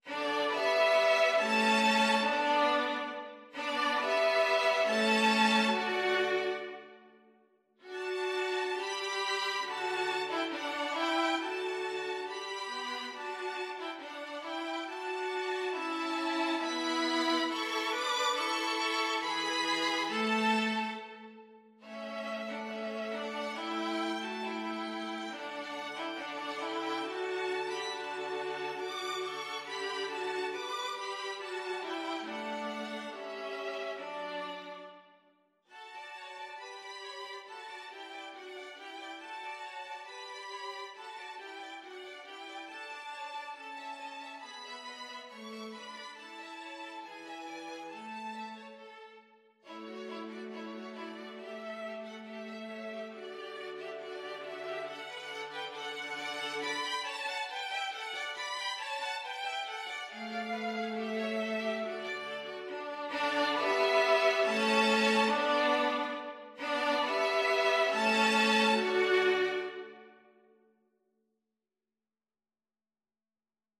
Violin 1Violin 2Viola
D major (Sounding Pitch) (View more D major Music for 2-violins-viola )
2/4 (View more 2/4 Music)
Andantino = c.69 (View more music marked Andantino)
Traditional (View more Traditional 2-violins-viola Music)